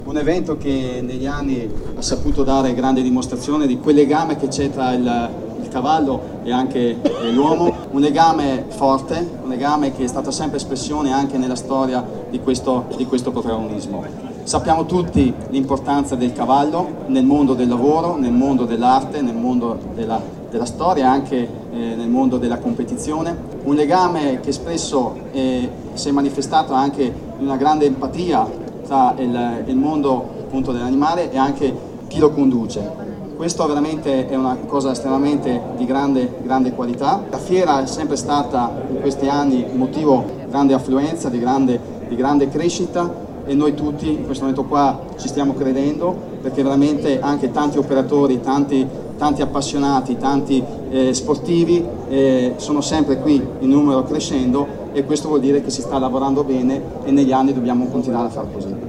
Presenti all’inaugurazione, intervistati dalla nostra corrispondente
il vicepresidente della Provincia David Di Michele